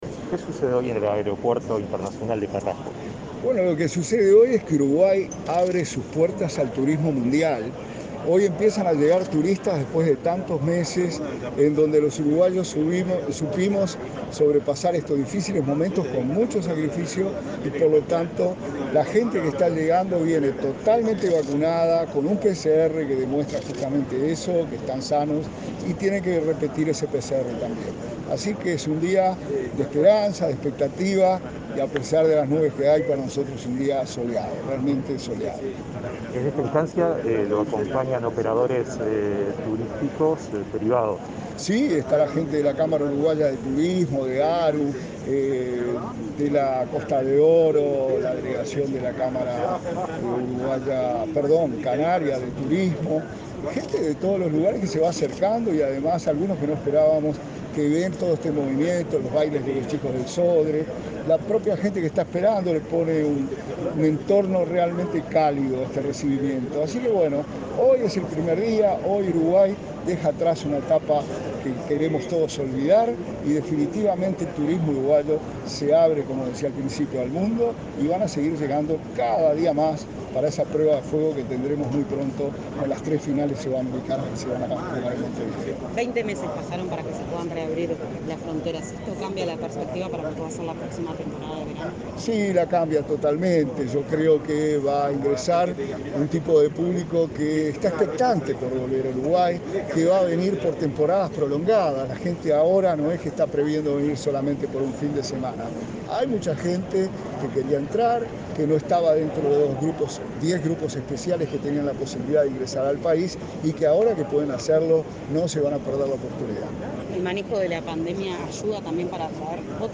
Declaraciones del subsecretario de Turismo, Remo Monzeglio
Declaraciones del subsecretario de Turismo, Remo Monzeglio 01/11/2021 Compartir Facebook X Copiar enlace WhatsApp LinkedIn Autoridades del Ministerio de Turismo recibieron, este 1.º de noviembre, a los primeros visitantes que llegan a Uruguay, a través del aeropuerto de Carrasco, tras la reapertura de fronteras. Tras el evento, el subsecretario Remo Monzeglio efectuó declaraciones a Comunicación Presidencial.